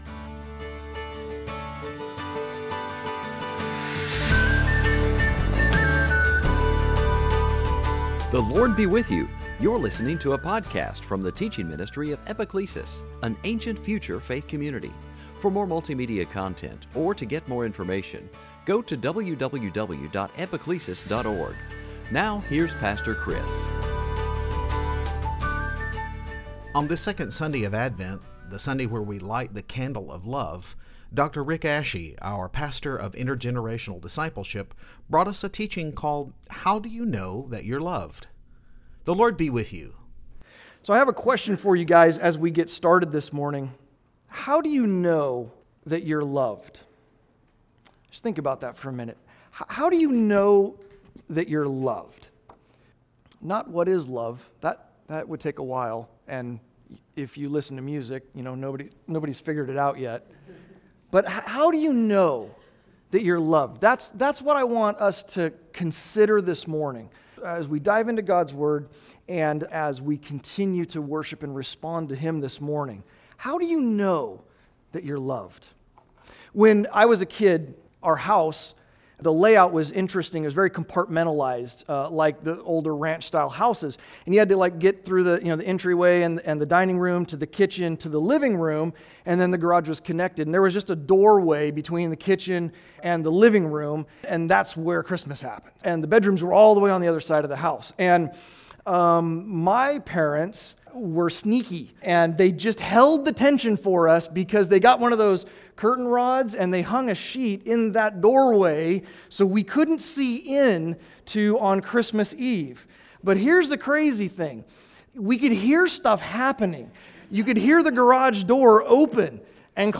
Series: Sunday Teaching On this second Sunday of Advent